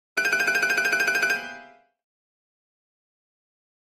Comedy Piano Trill 3 - Danger